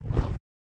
PixelPerfectionCE/assets/minecraft/sounds/mob/polarbear/step1.ogg at mc116